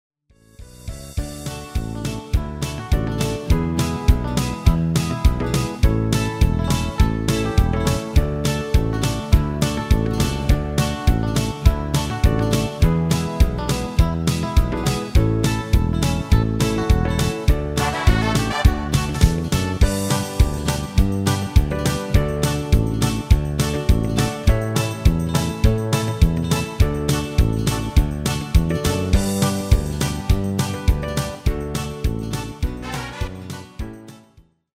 Demo/Koop midifile
Genre: Nederlands amusement / volks
- GM = General Midi level 1
- Géén vocal harmony tracks
Demo's zijn eigen opnames van onze digitale arrangementen.